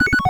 retro_beeps_success_04.wav